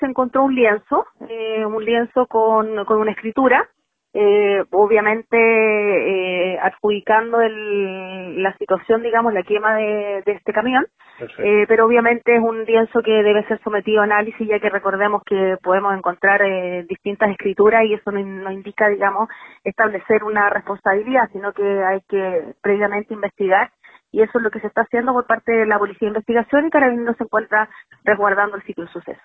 fiscal2.mp3